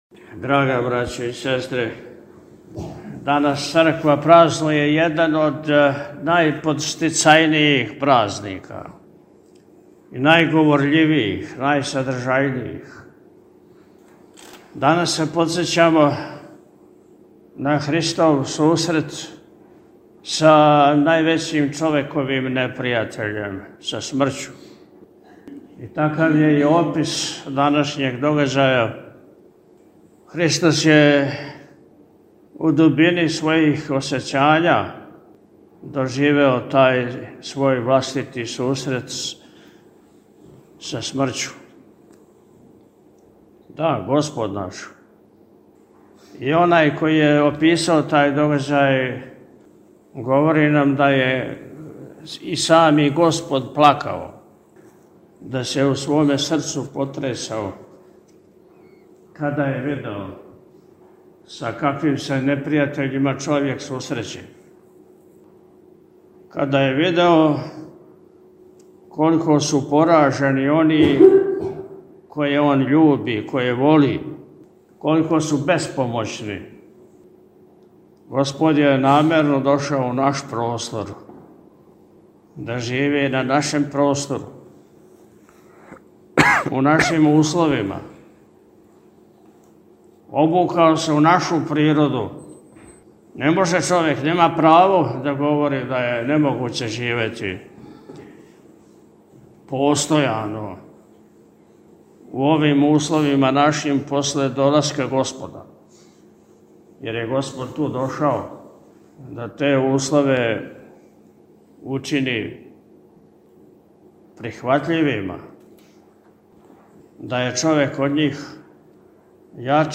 Након отпуста Митрополит је, у пастирској беседи, поред осталог, рекао: – Данас Црква празнује један од најподстицајнијих празника, најговорљивијих, најсадржајнијих. Данас се подсећамо на Христов сусрет са највећим човековим непријатељем, са смрћу.